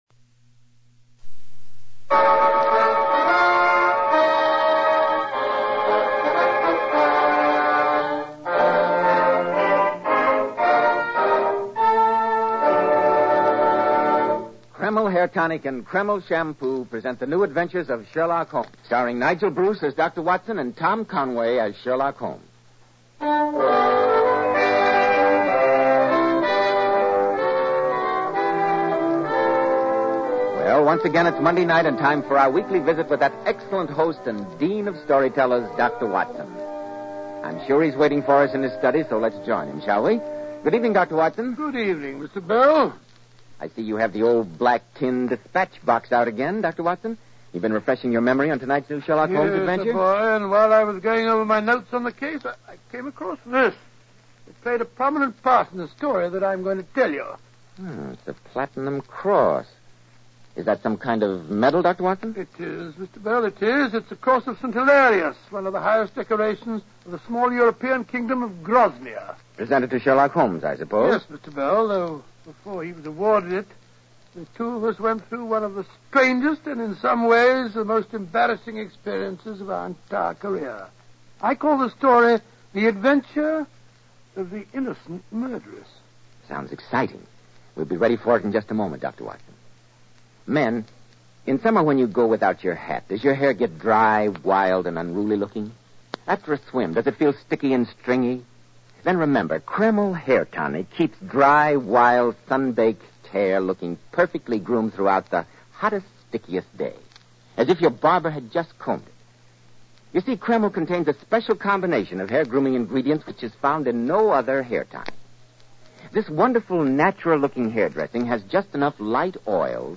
Radio Show Drama with Sherlock Holmes - The Innocent Murderess 1947